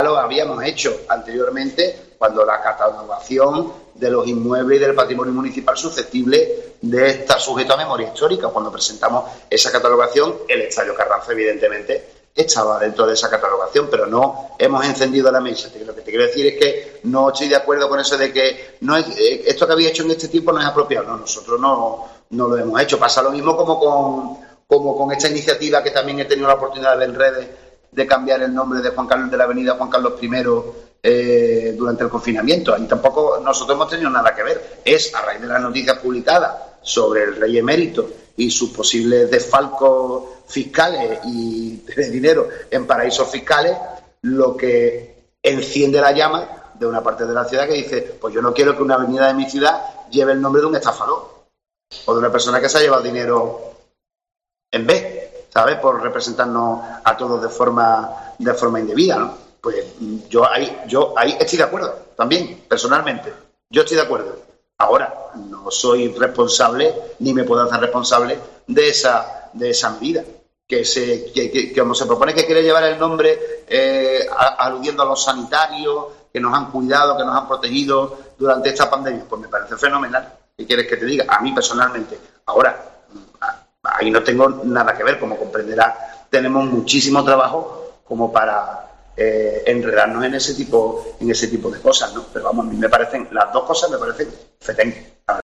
Kichi habla sobre el cambio de nomenclatura de la Avenida Juan Carlos I